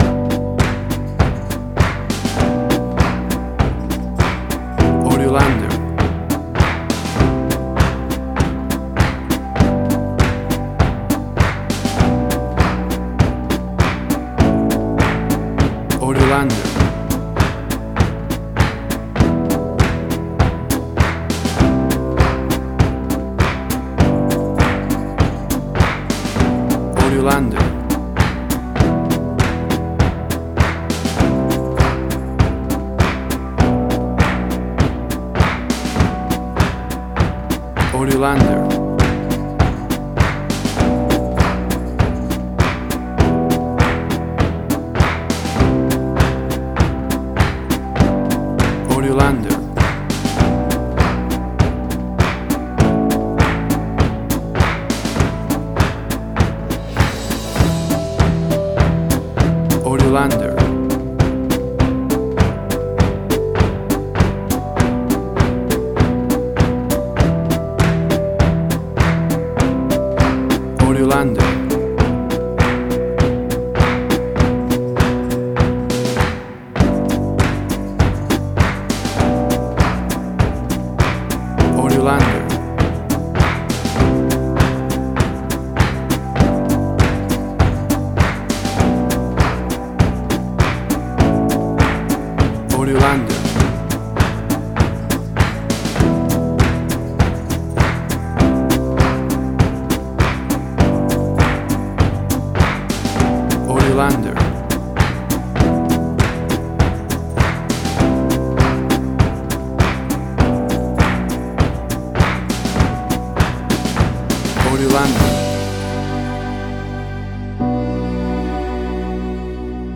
Suspense, Drama, Quirky, Emotional.
WAV Sample Rate: 16-Bit stereo, 44.1 kHz
Tempo (BPM): 100